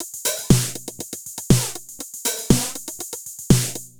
Beat 05 No Kick (120BPM).wav